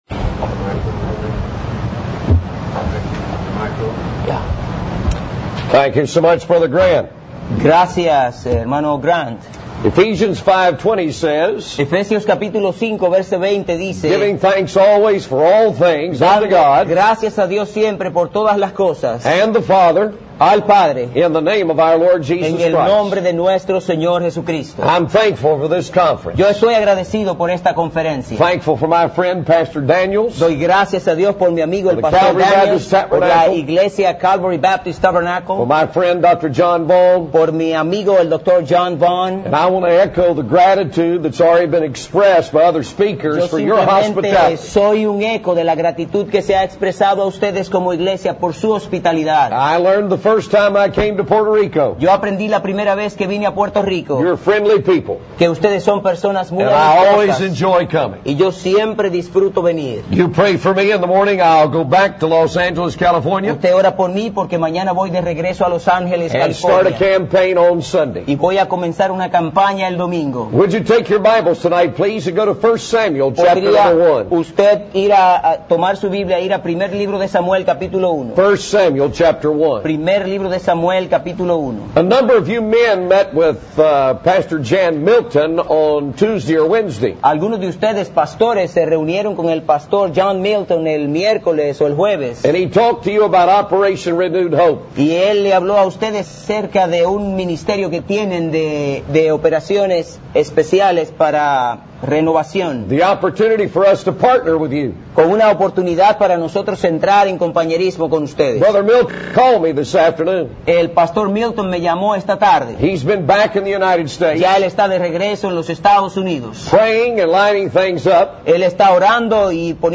This message includes Spanish translation by an interpreter.